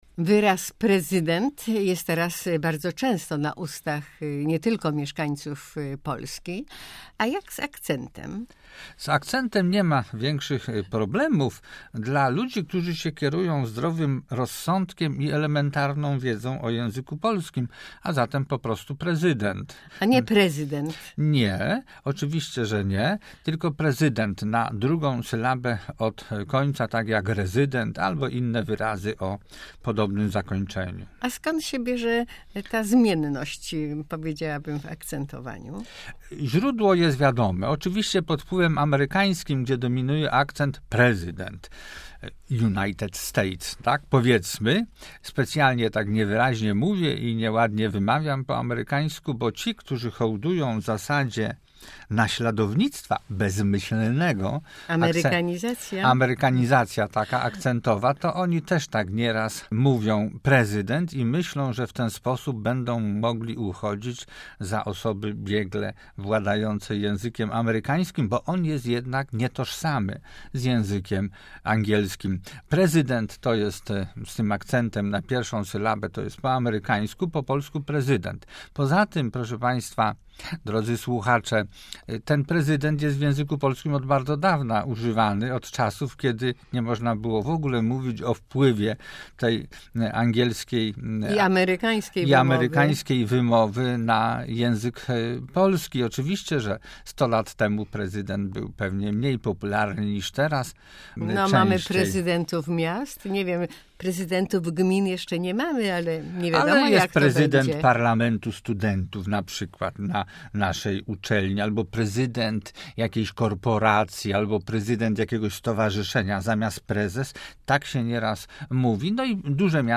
Nie akcentujemy na pierwszej sylabie!